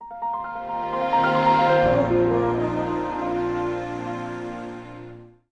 logon-sound.mp3